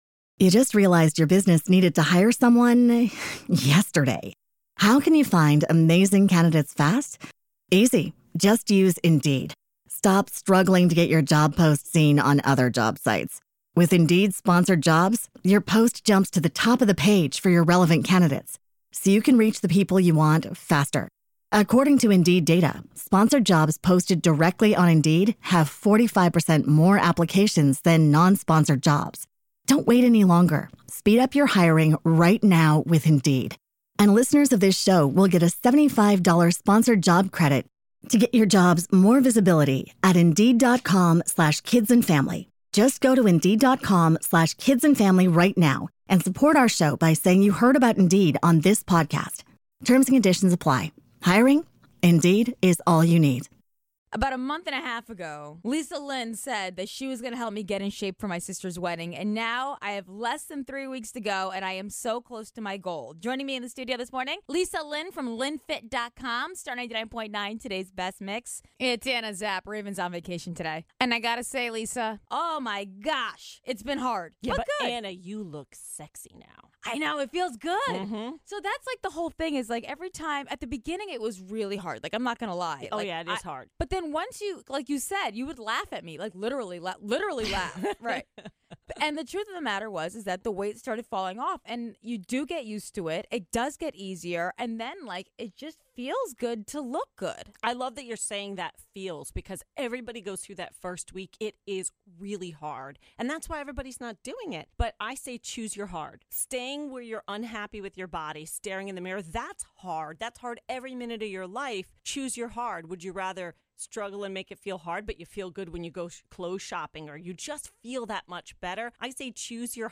back in studio